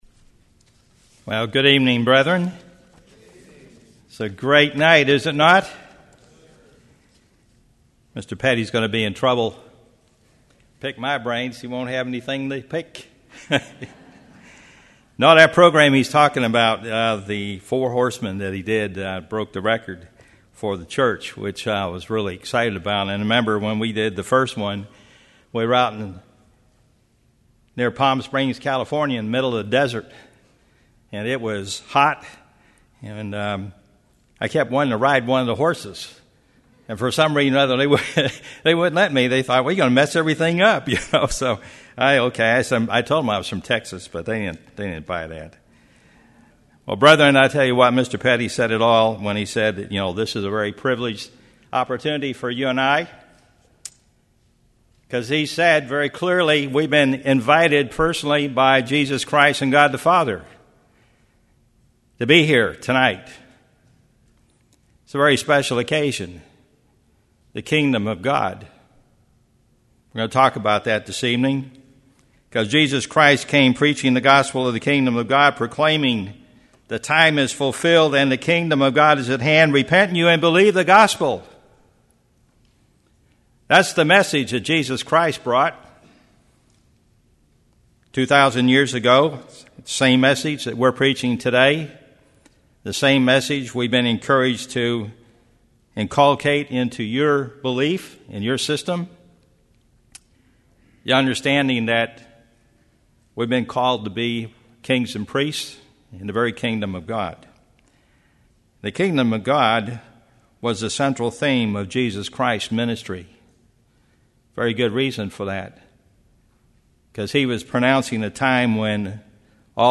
This sermon was given at the New Braunfels, Texas 2013 Feast site.